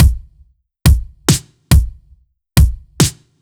Index of /musicradar/french-house-chillout-samples/140bpm/Beats
FHC_BeatD_140-03_KickSnare.wav